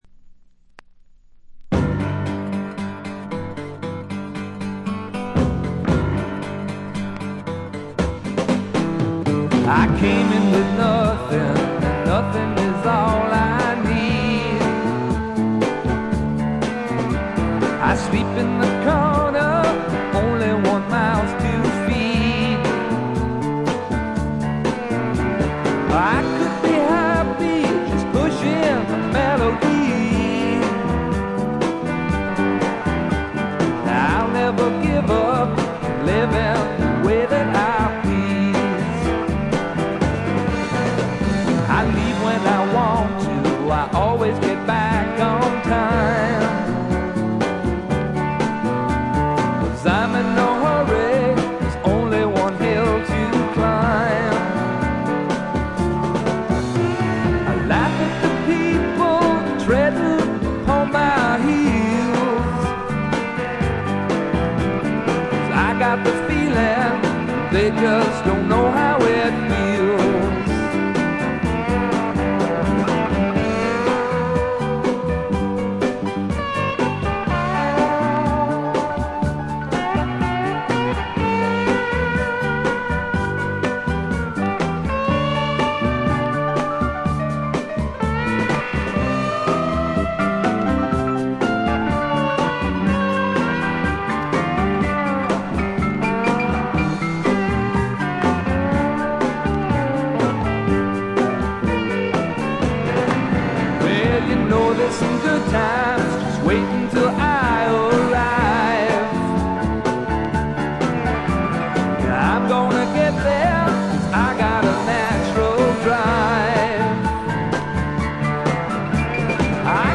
バックグラウンドノイズ、チリプチがやや多め大きめです。
試聴曲は現品からの取り込み音源です。
Vocals, Acoustic Guitar
Pedal Steel Guitar